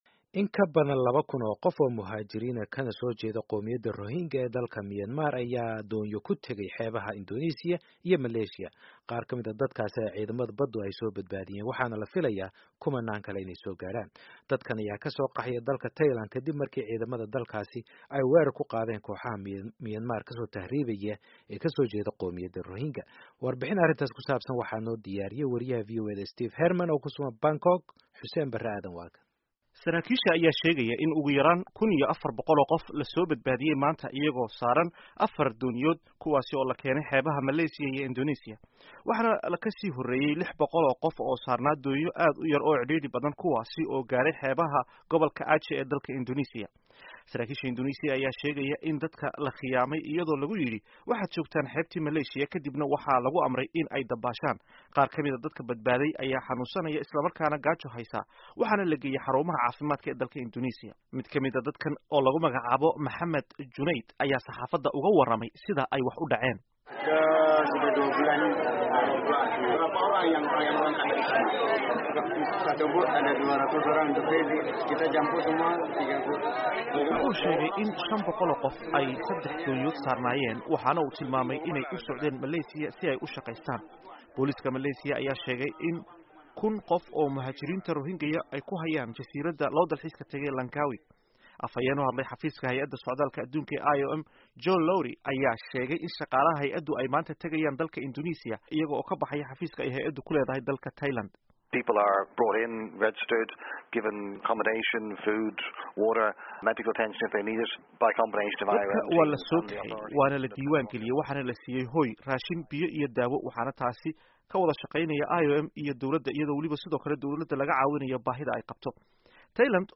Warbixinta Muslimiinta Burma